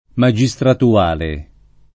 [ ma J i S tratu- # le ]